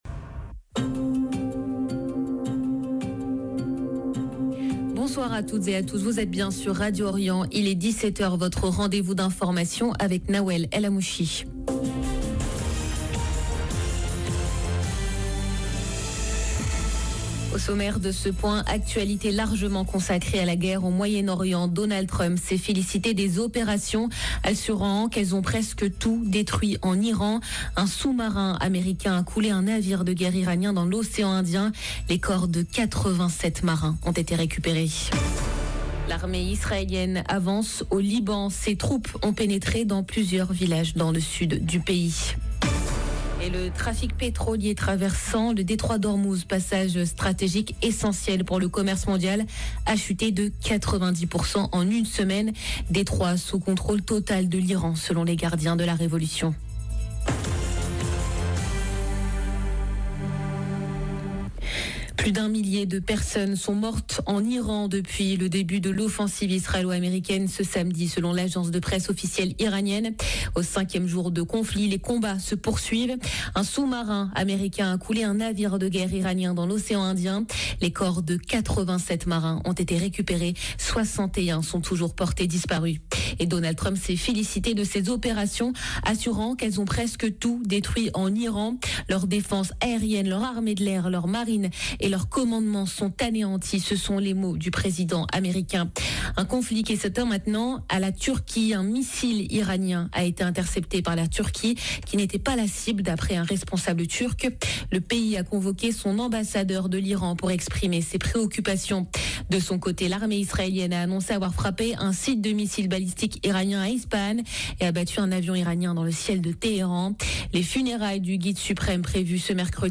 Journal de 17H Au sommaire: Donald Trump se félicite des frappes américaines en Iran, affirmant qu’elles ont « presque tout détruit ».